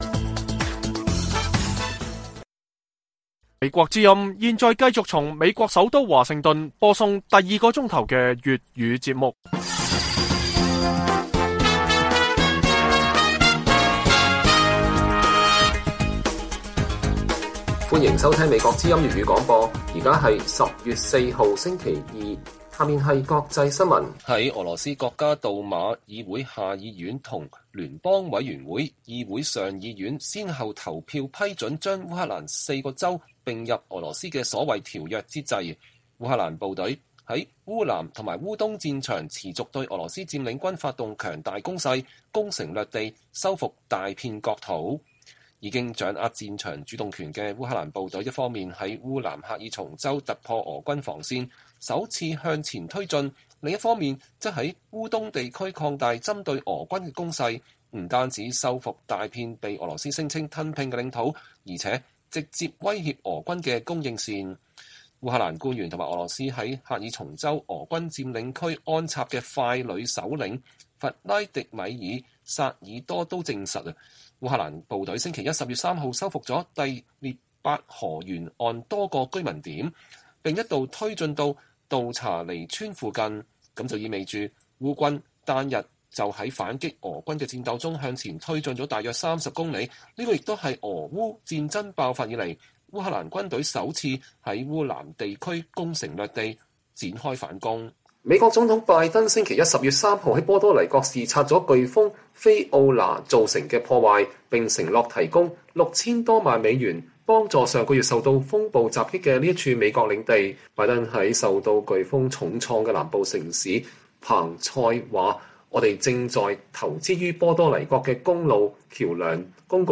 粵語新聞 晚上10-11點: 烏克蘭軍隊在烏南烏東繼續挺進收復大片國土